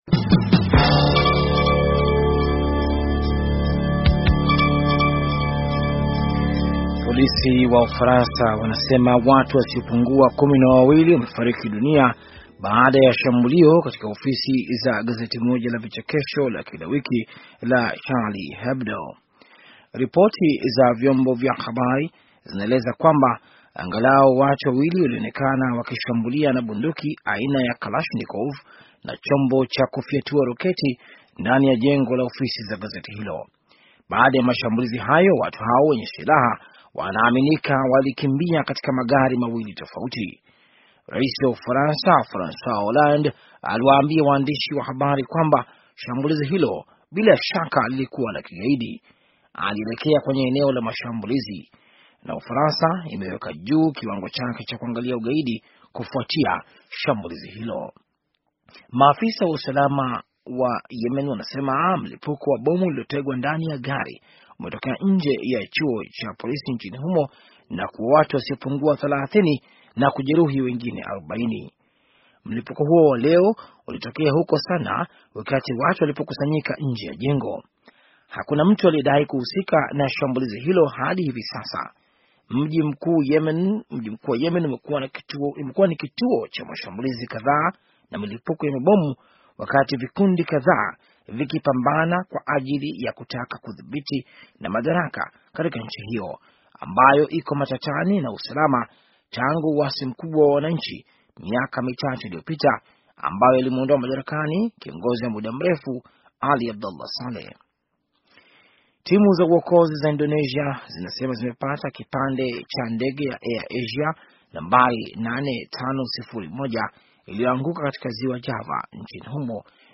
Taarifa ya habari - 5:35